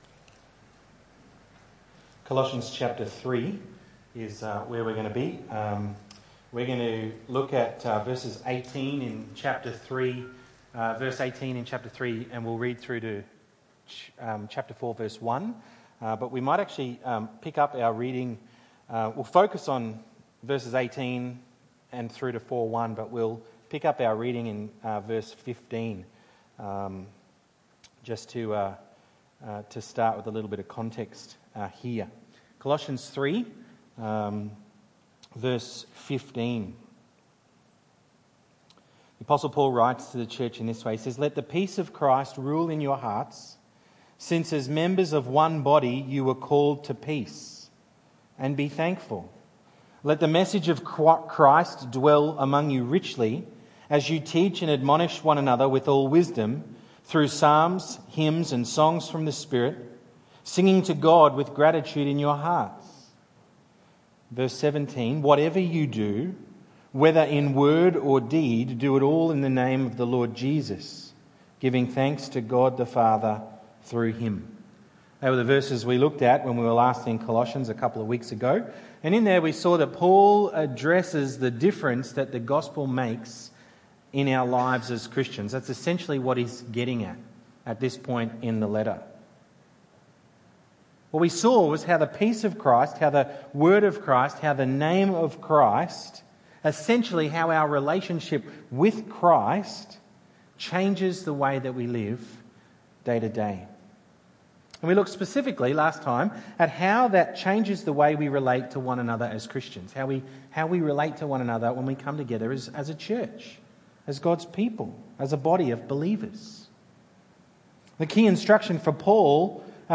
Colossians 3:18-4:1 Tagged with Sunday Evening